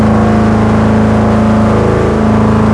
mere63_low.wav